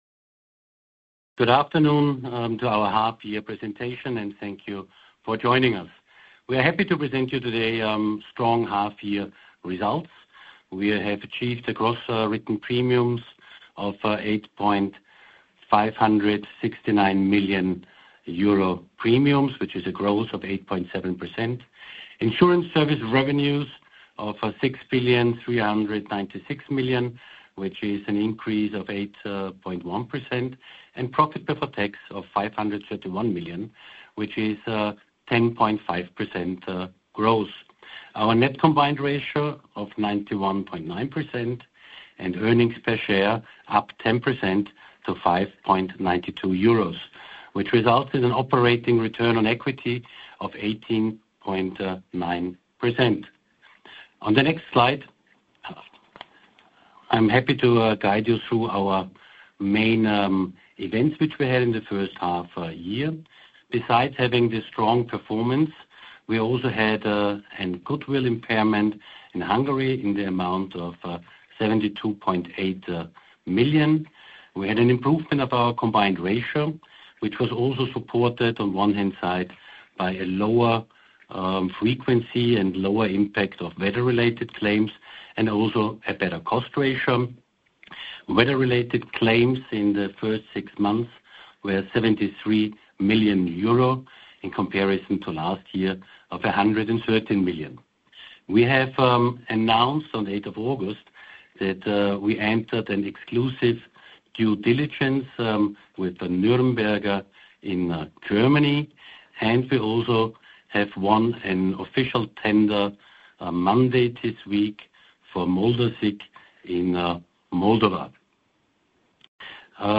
6M 2025 VIG Telefonkonferenz (english only) mp3 Datei herunterladen